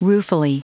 Prononciation du mot ruefully en anglais (fichier audio)
Prononciation du mot : ruefully